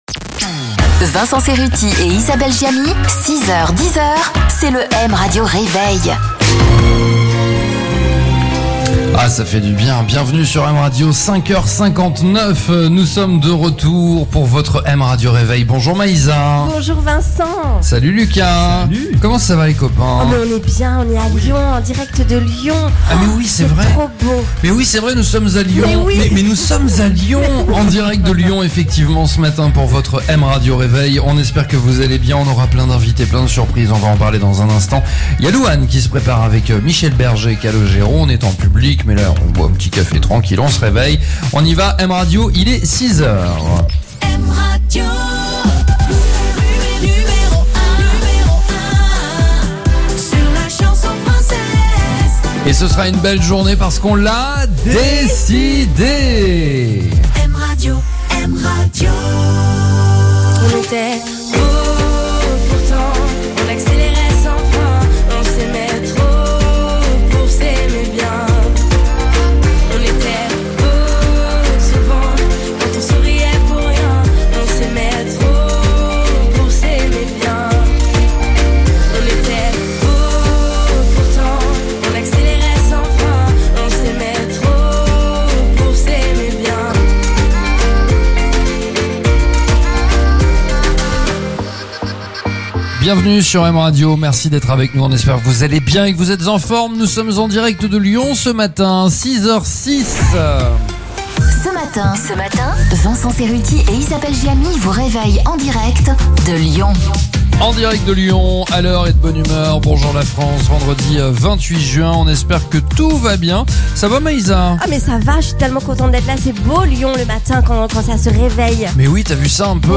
en direct de Lyon - 28 juin 2024